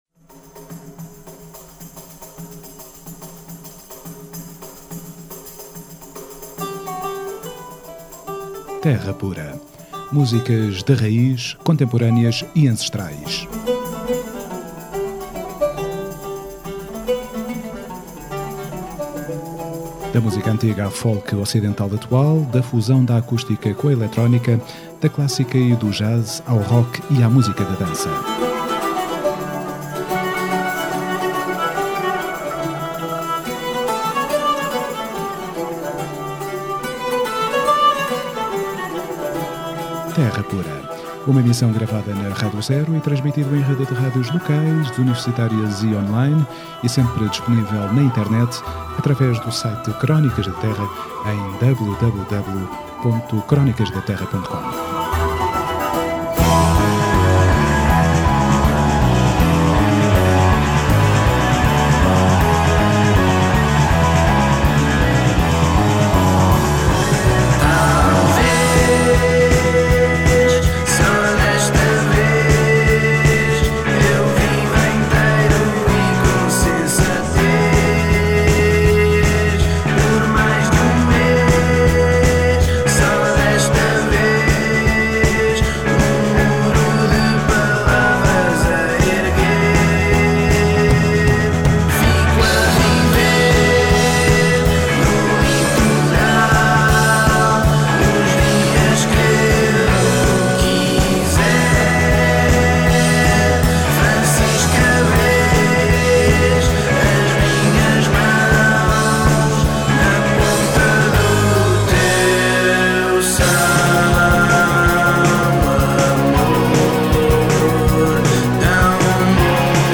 Na Terra Pura colhemos algum do melhor rock nacional actual feito por rapazes na casa dos 20 anos cuja extensa dieta musical inclui o psicadelismo anglo-saxónico dos anos 60 e o progressivo dos anos 70.
Entrevista Capitão Fausto